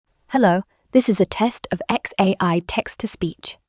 Transformer le texte en discours naturel avec le Grok TTS de xAI